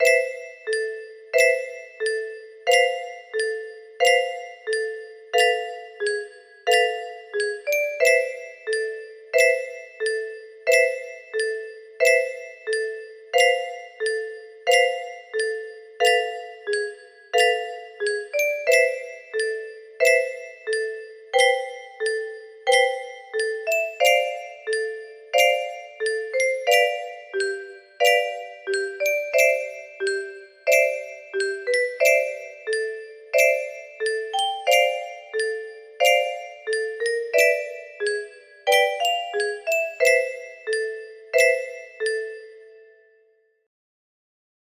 Misty night music box melody